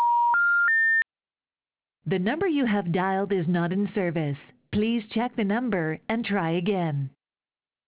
Known telemarketers get a tone and a message saying the number has been disconnected.
Disconnected.wav